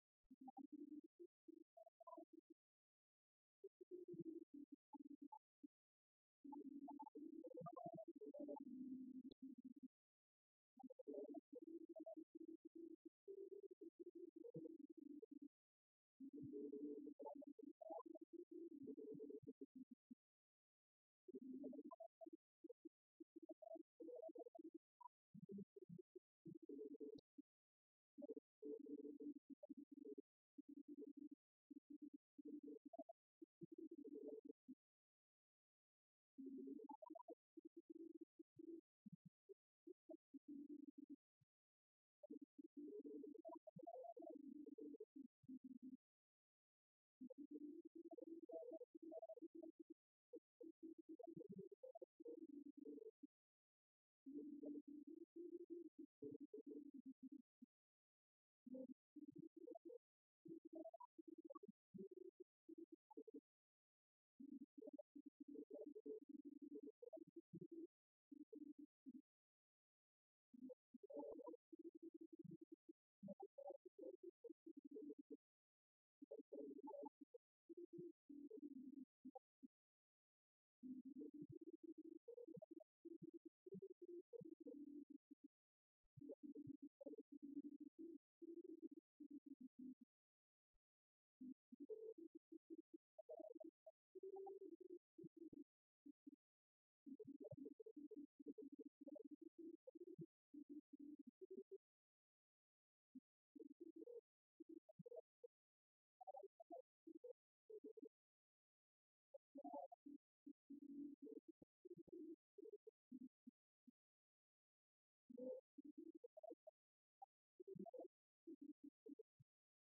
messe anniversaire de la Madone à la chapelle du Bon Secours, Abbaye de Bellefontaine
cérémonie religieuse
prière, cantique
Pièce musicale inédite